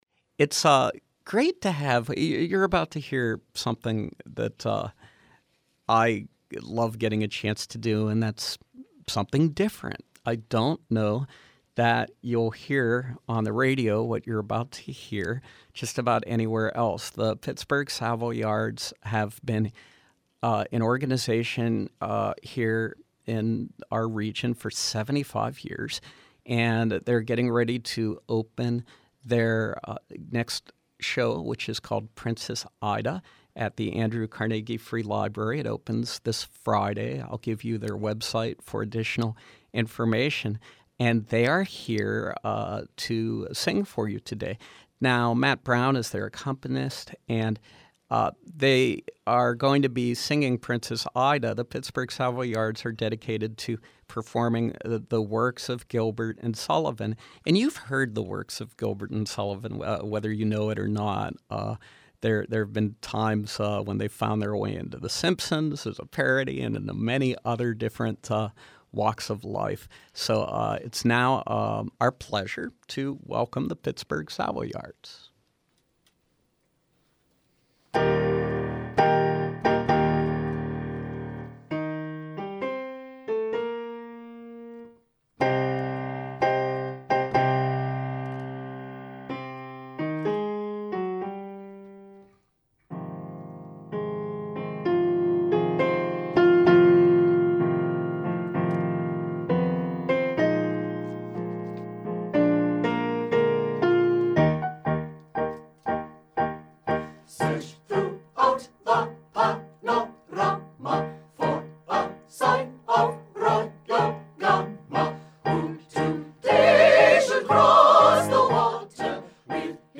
From 10/5/13: A live performance by The Pittsburgh Savoyards in advance of their production of Princess Ida, 10/11 through 10/20, Andrew Carnegie Free Library & Music Hall, Carnegie